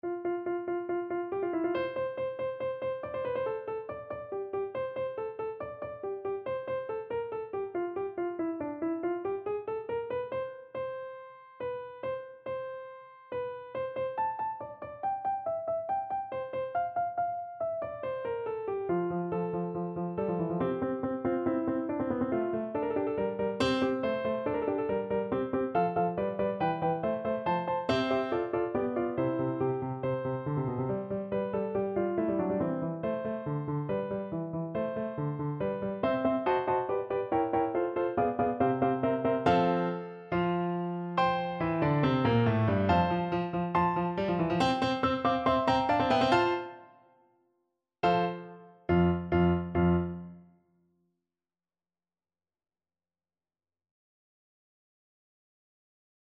Play (or use space bar on your keyboard) Pause Music Playalong - Piano Accompaniment Playalong Band Accompaniment not yet available reset tempo print settings full screen
= 140 Allegro (View more music marked Allegro)
F major (Sounding Pitch) (View more F major Music for Flute )
4/4 (View more 4/4 Music)
Classical (View more Classical Flute Music)